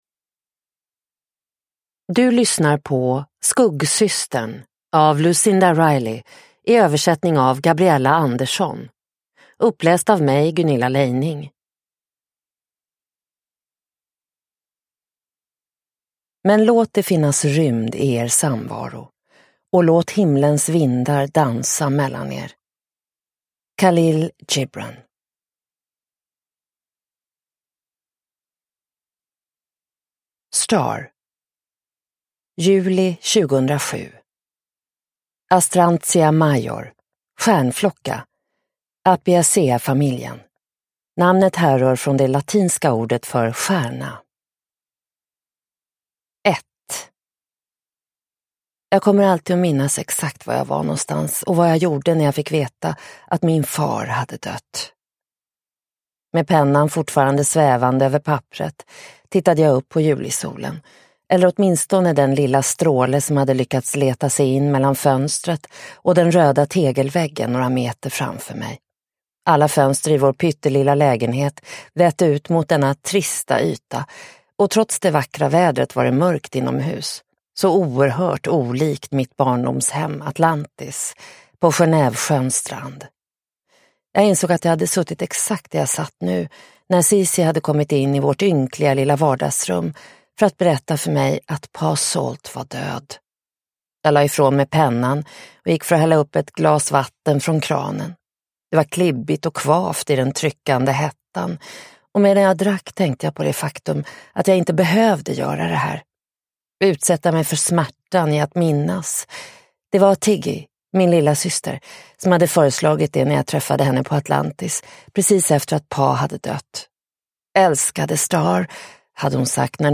Skuggsystern : Stars bok – Ljudbok – Laddas ner
Uppläsare